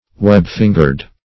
Search Result for " web-fingered" : The Collaborative International Dictionary of English v.0.48: Web-fingered \Web"-fin`gered\, a. Having the fingers united by a web for a considerable part of their length.